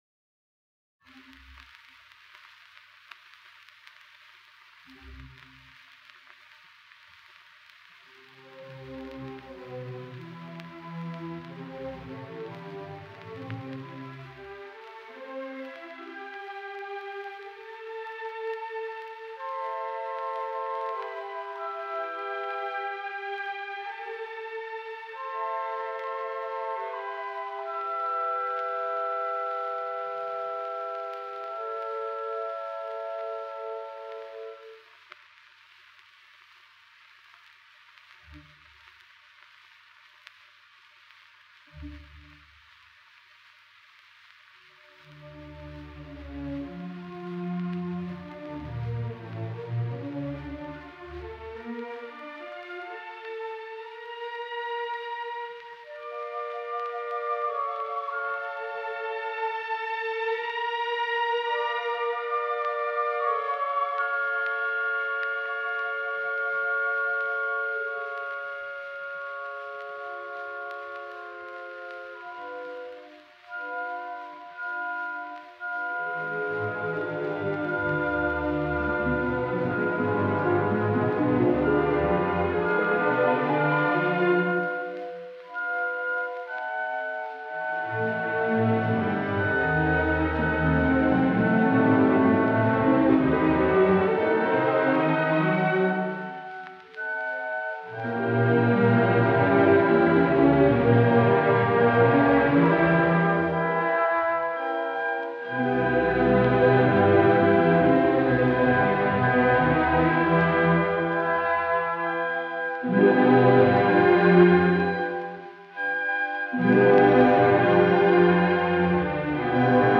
Los preludios . Poema sinfónico.